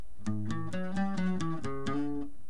1b (bajosexto)